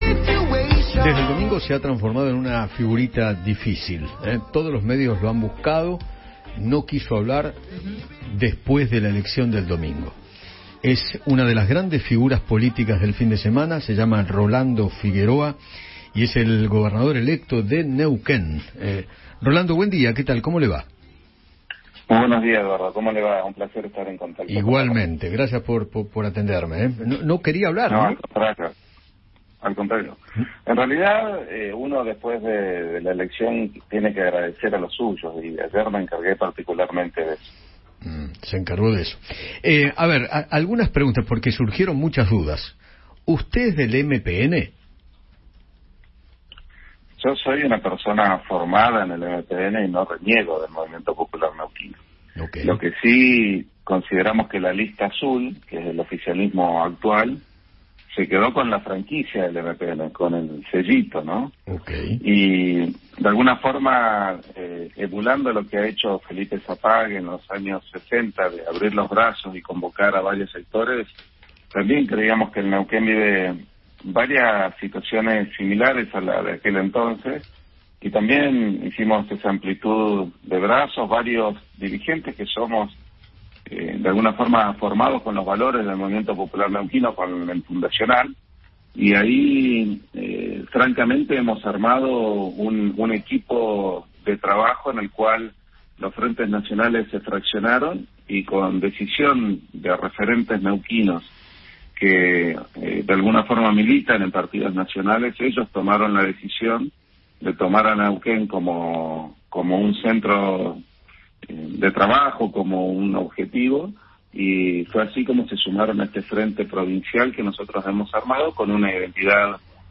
Rolando Figueroa, electo gobernador de Neuquén, conversó con Eduardo Feinmann tras su triunfo en las urnas y destacó el frente provincial conformado.